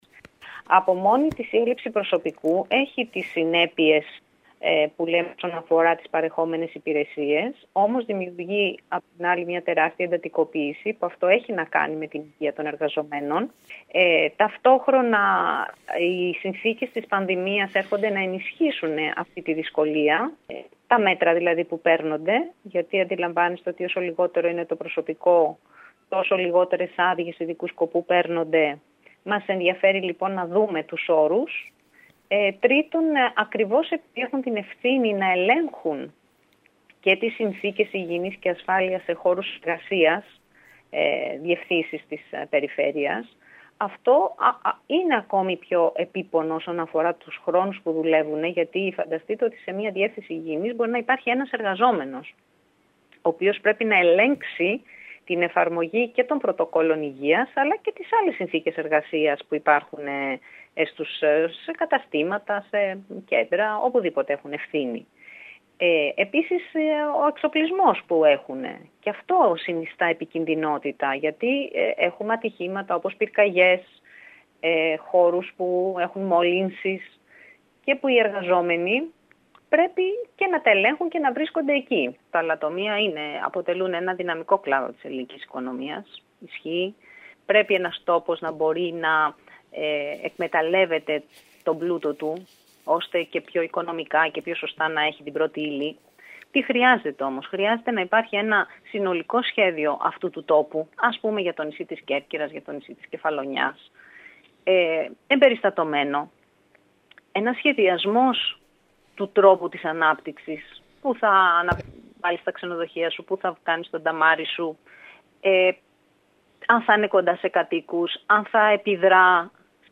Η έλλειψη προσωπικού από μόνη της θέτει θέματα ασφάλειας και υγιεινής των εργαζομένων δηλώνει χαρακτηριστικά η Αλεξάνδρα Μπαλού επικεφαλής της παράταξης μιλώντας σήμερα στην ΕΡΑ ΚΕΡΚΥΡΑΣ.